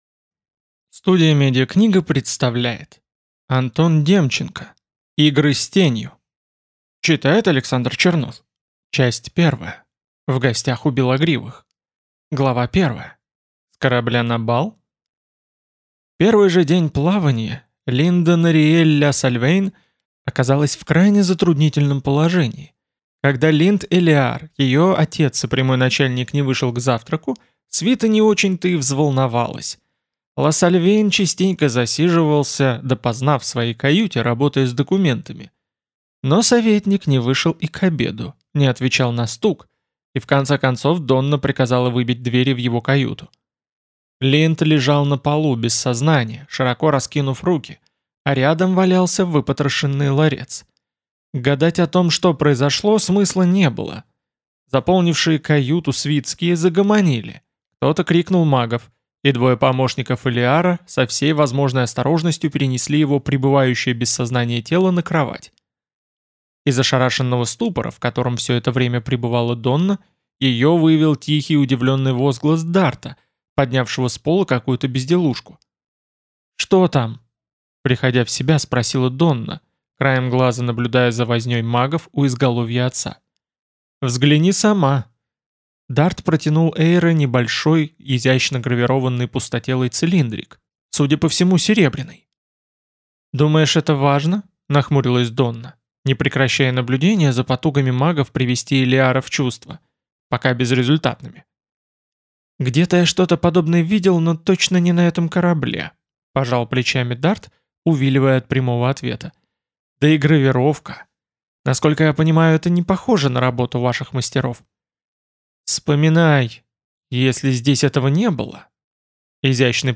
Аудиокнига Игры с Тенью | Библиотека аудиокниг